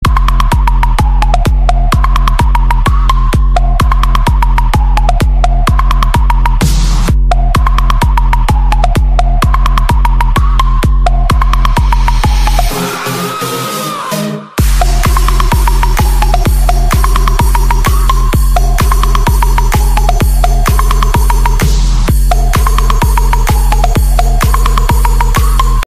Categoría Electrónica